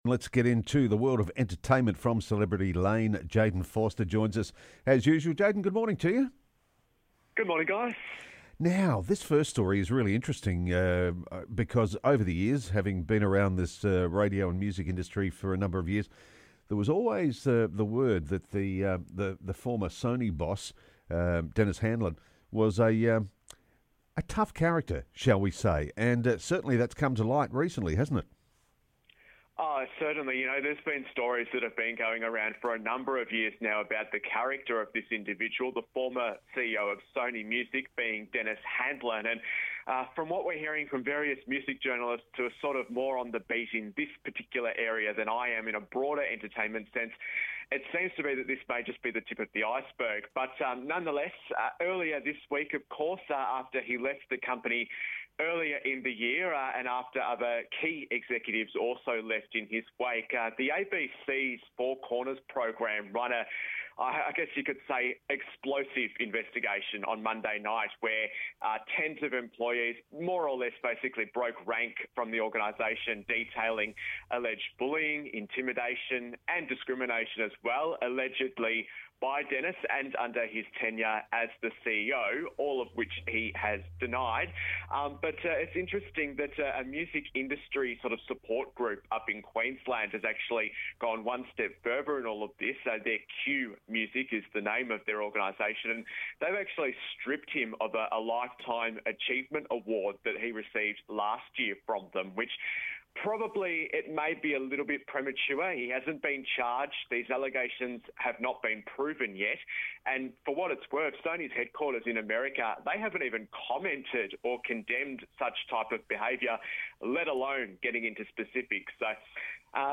entertainment report